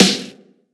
Medicated Snare 2.wav